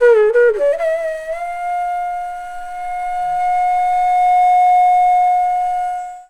FLUTE-A10 -L.wav